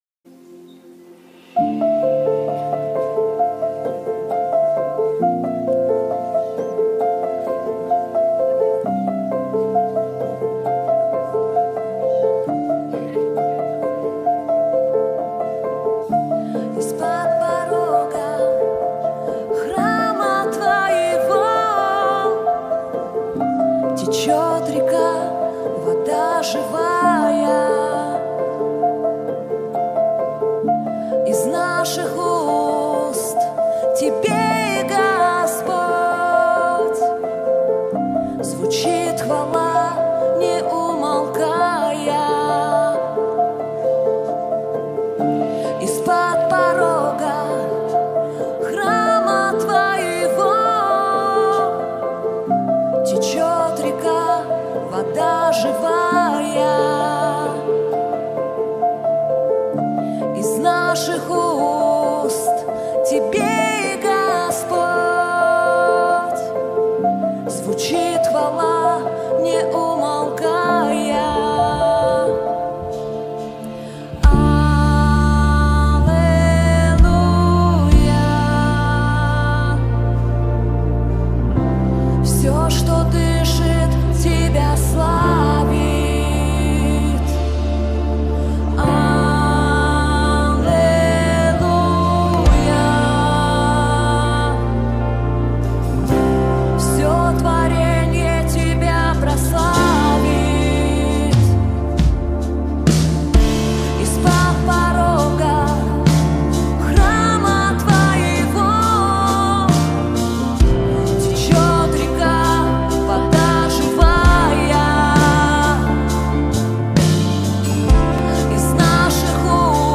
песня
1625 просмотров 1065 прослушиваний 149 скачиваний BPM: 133